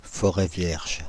Ääntäminen
ÄäntäminenParis:
• IPA: [fɔ.ʁɛ vjɛʒ]